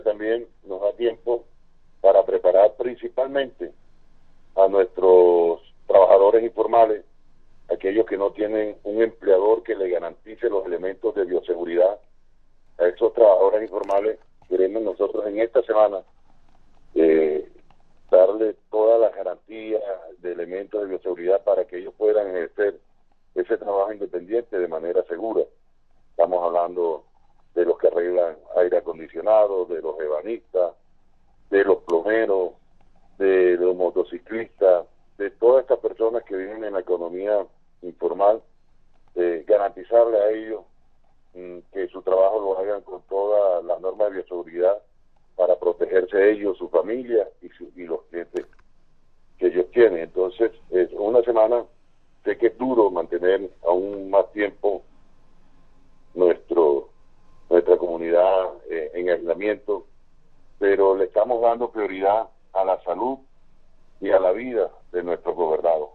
Alcalde de Baranoa respalda extensión de la cuarentena: ‘es duro pero estamos protegiendo la salud y la vida’